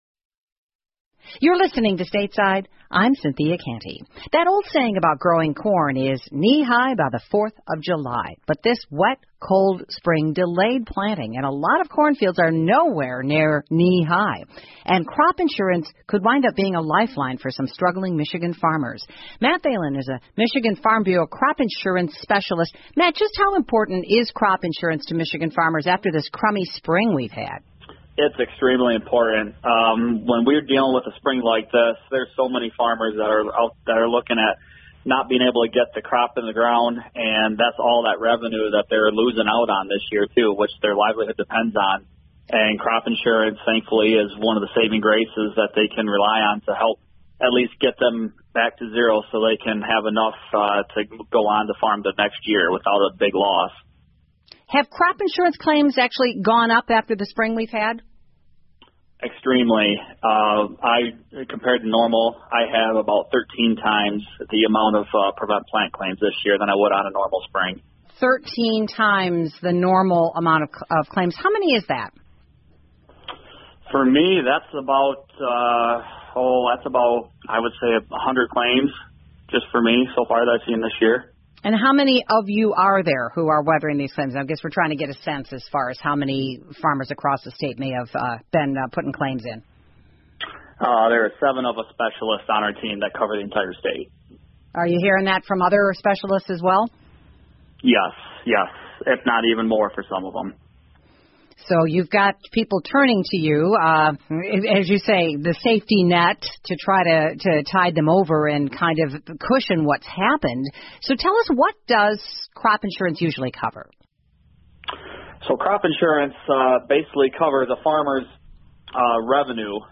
密歇根新闻广播 农民依靠保险度过恶劣天气 听力文件下载—在线英语听力室